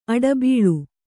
♪ aḍabīḷu